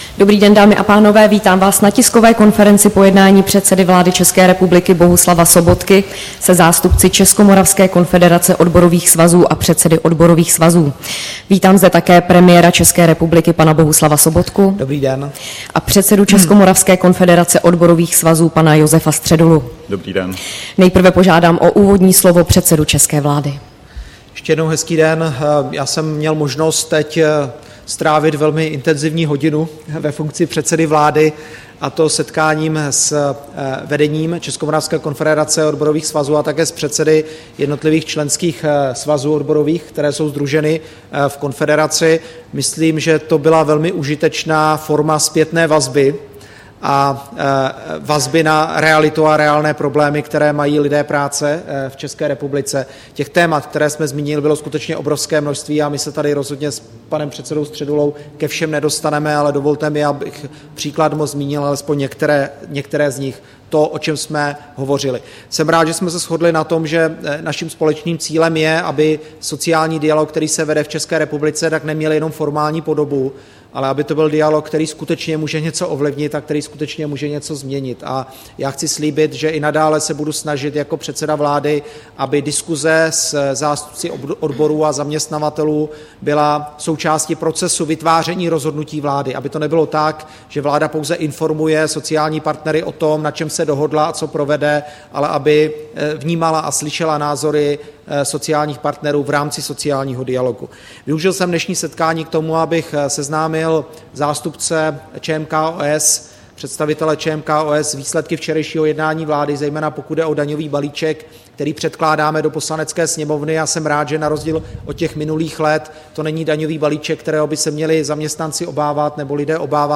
Tisková konference po jednání se zástupci ČMKOS